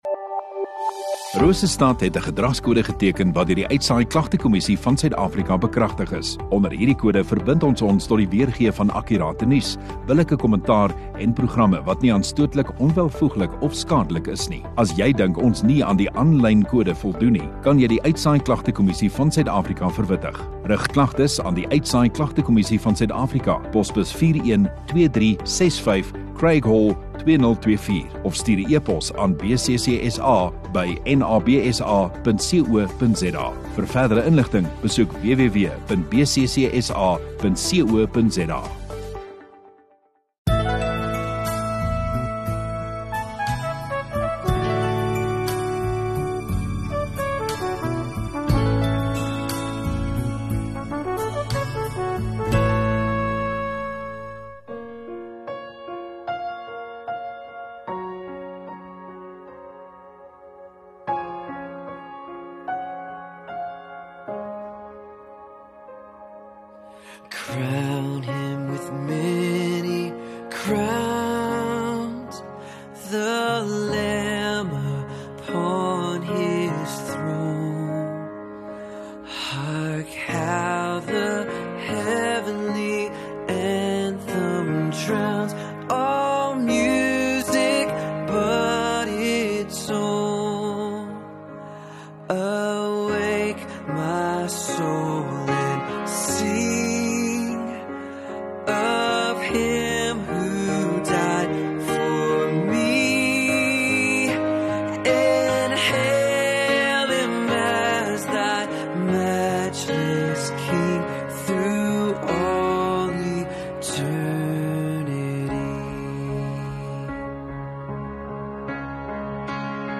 1 Mar Sondagoggend Erediens